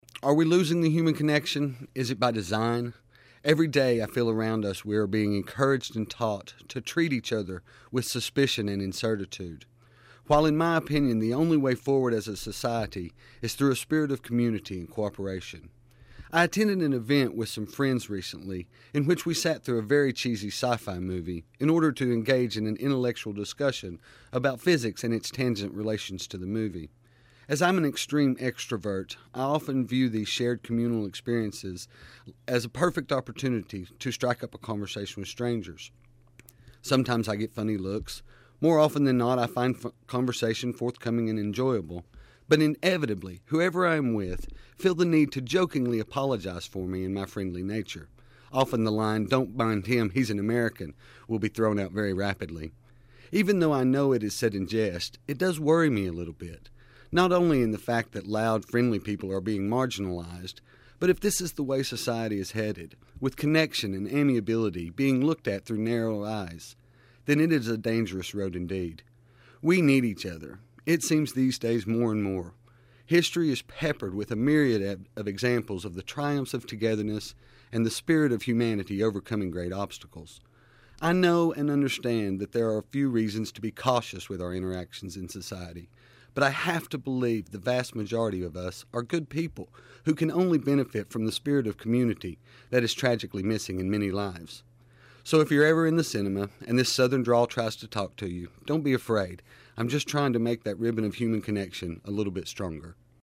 an American living in Devon who will gives us perspectives on life.